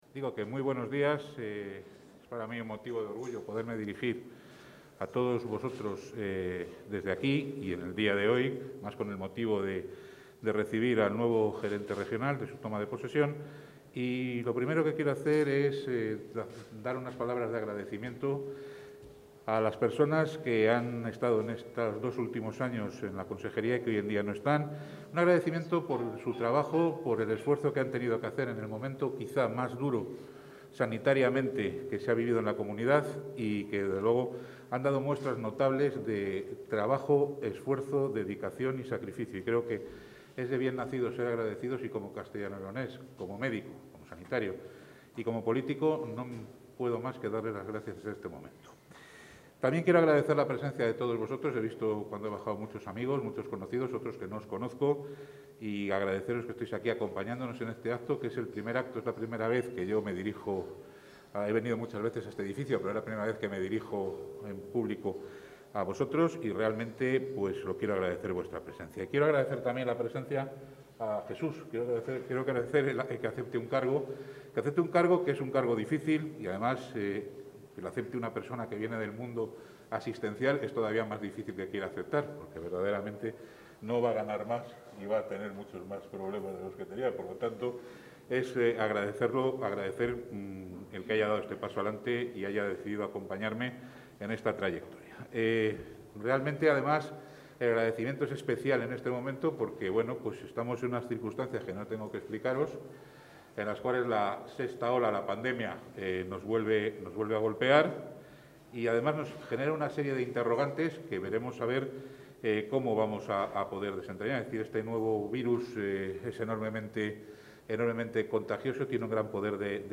Audio consejero.
El consejero de Sanidad, Alejandro Vázquez, ha presidido hoy la toma de posesión del nuevo director-gerente de la Gerencia Regional de Salud, Jesús García-Cruces